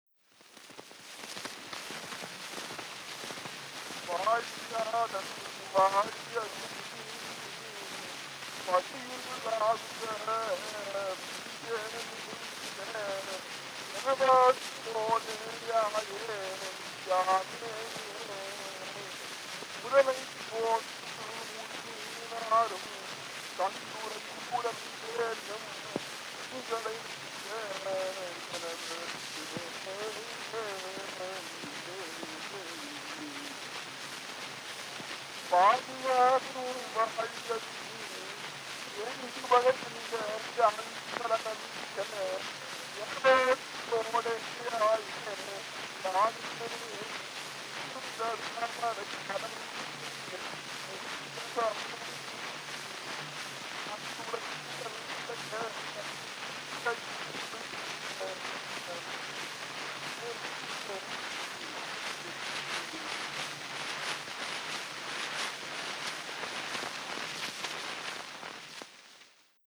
Historical sound recordings